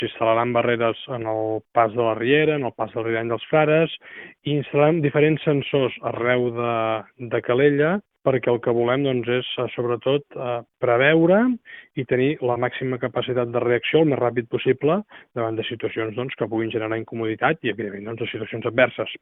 En declaracions a Ràdio Calella TV, l’alcalde Buch ha explicat que gràcies al nou sistema es podran automatitzar els tancaments de les rieres, que són punts problemàtics en dies de pluja intensa.